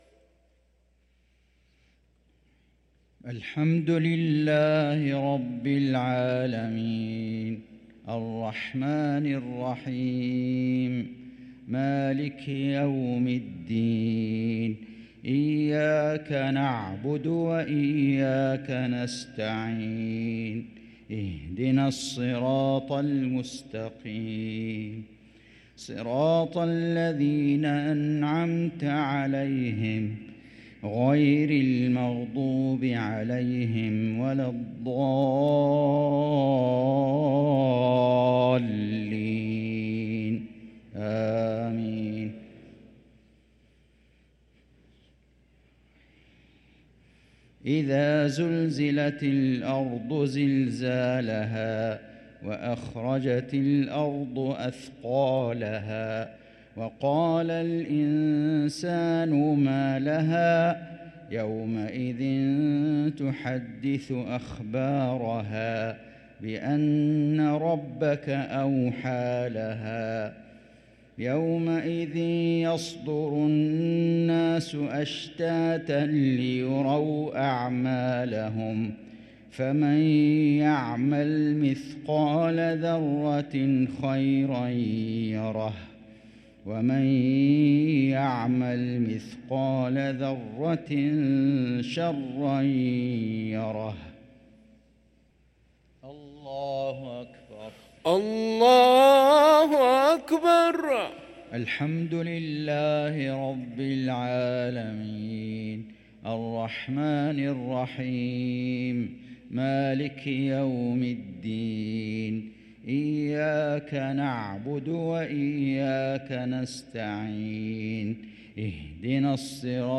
صلاة المغرب للقارئ فيصل غزاوي 9 ربيع الأول 1445 هـ